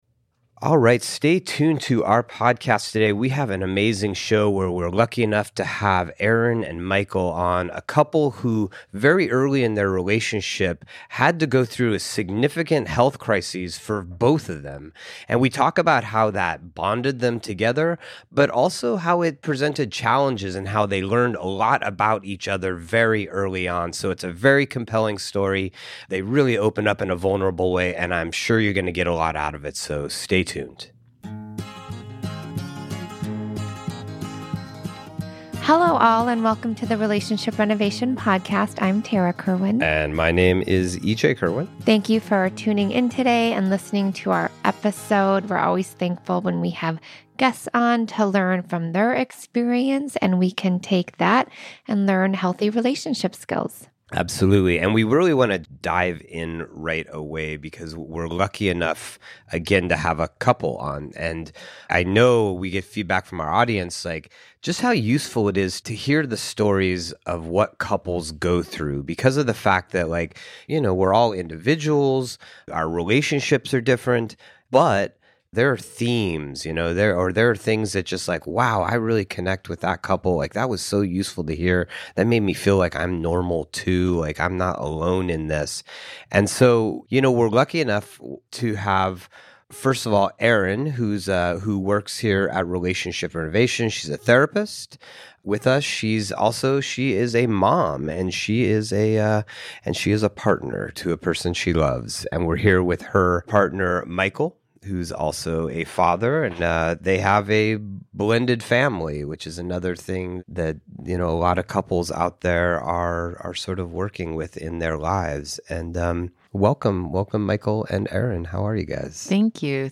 engage in a profound discussion with Dr. Warren Farrell, a renowned thought leader in the realm of relationships and men's mental health.